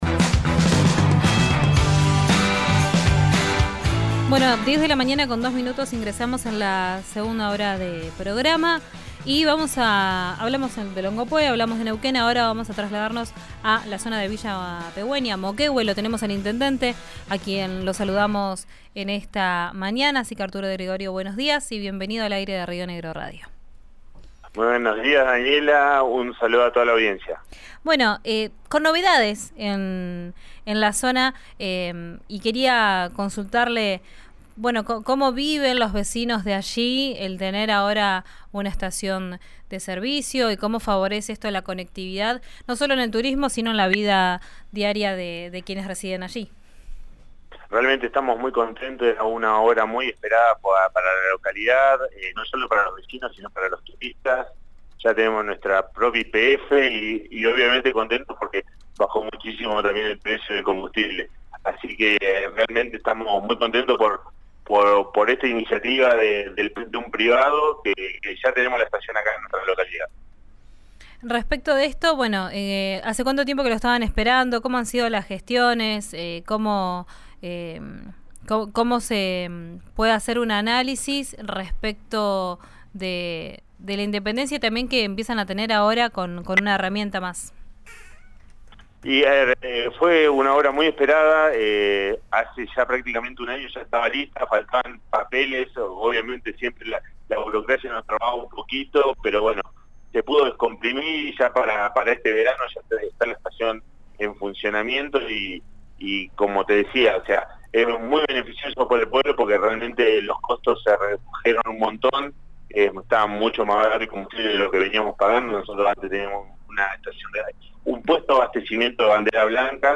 Escuchá a Arturo De Gregorio, intendente de Villa Pehuenia Moquehue, en RADIO RÍO NEGRO:
El jefe comunal dialogó con Ya es Tiempo y se mostró entusiasmado ante la nueva estación de servicio que se instaló en la villa turística de Neuquén.